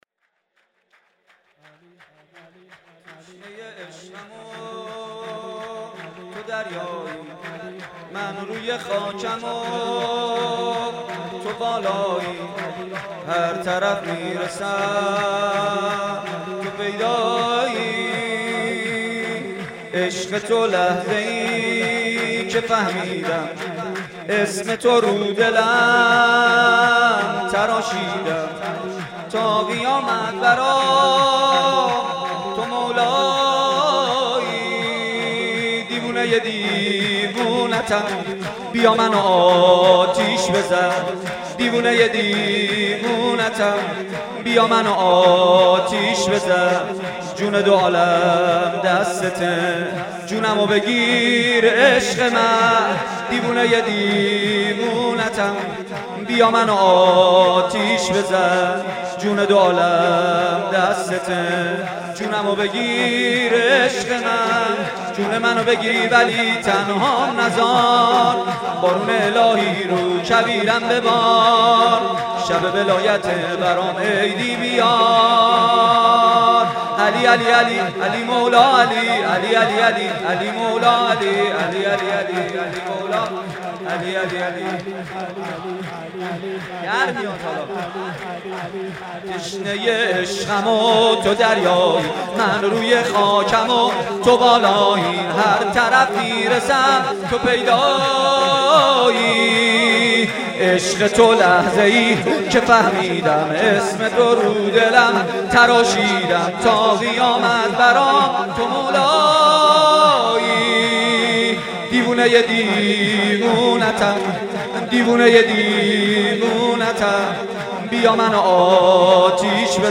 گلچین مولودی عید غدیر با نوای مداحان
فایل صوتی گلچین مولودی عید غدیر با نوای مداحان اهل بیت (ع) را اینجا دریافت کنید.